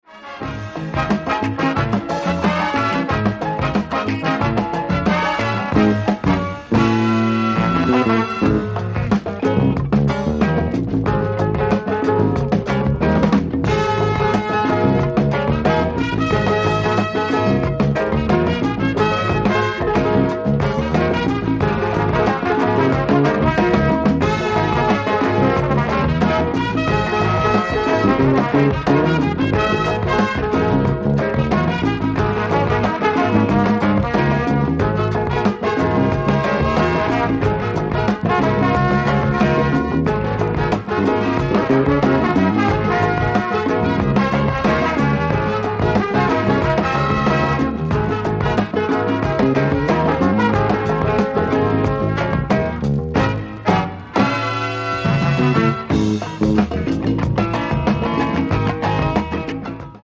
Heavy latin funk LP from the early 70s.